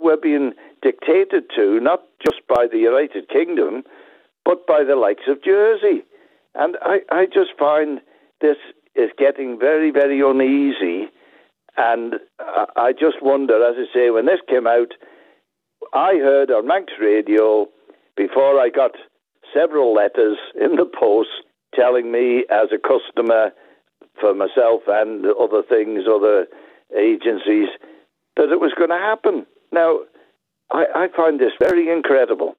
Speaking on the Mannin Line, Mr Gelling also expressed concern that customers found out through the media, rather than directly from the bank: Listen to this audio